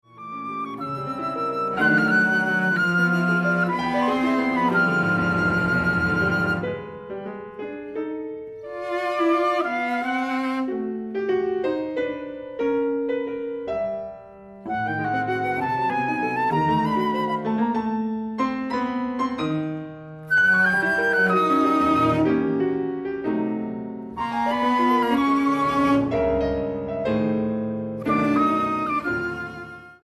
para flauta, violonchelo y piano